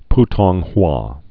(ptônghwä, -wä, -tŏng-)